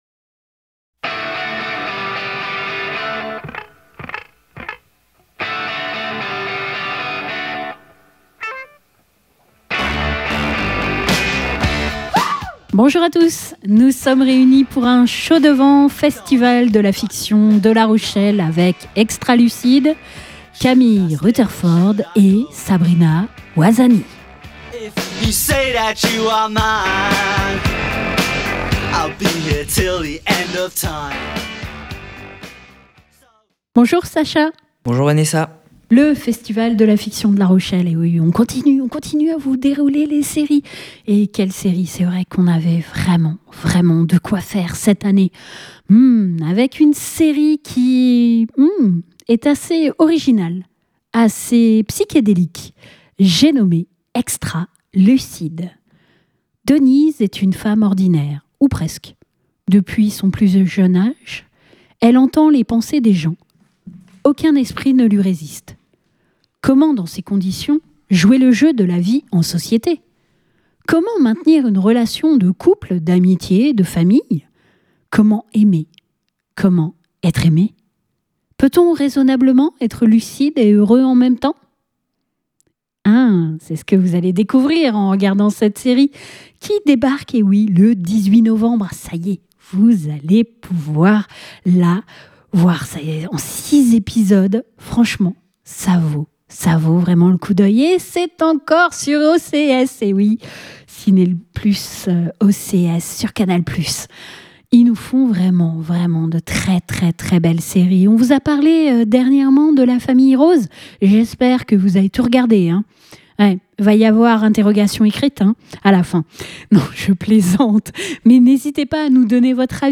Le duo complice de Camille Rutherford et Sabrina Ouazani interprète avec brio ses deux amies dont l'une entend les pensées de son entourage mais est-ce un don ou une malédiction ? Nous avons eu la chance d'avoir les deux actrices en entrevue.